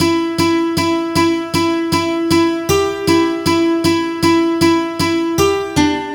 Track 11 - Guitar 01.wav